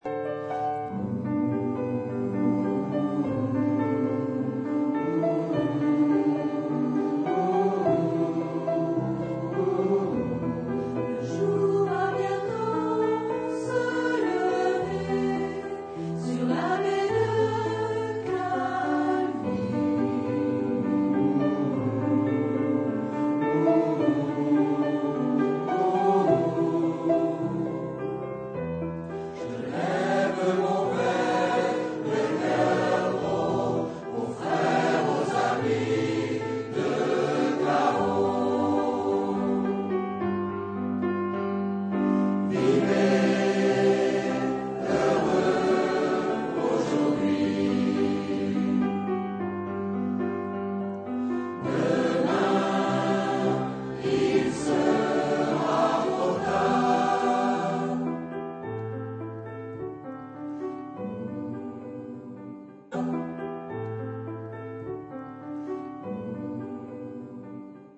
Genre : Chanson française